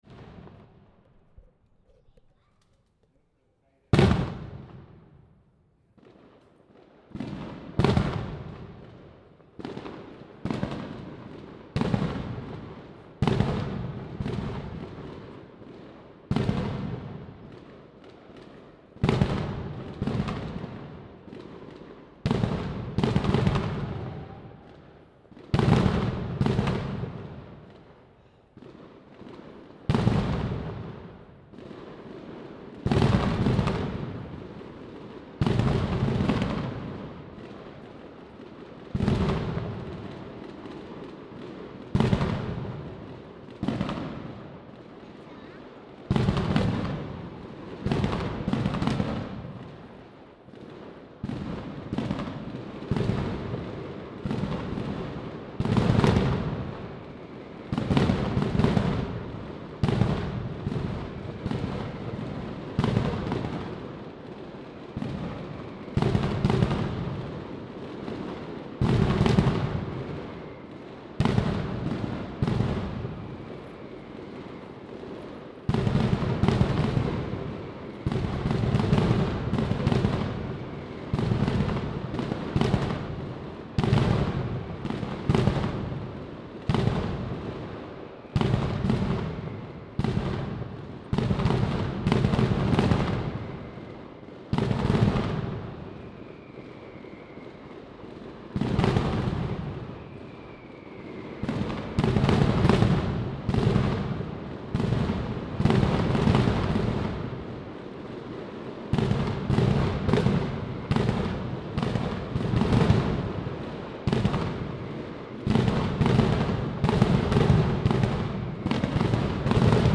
33 TRONADA PREGÓ Pirotècnia Igual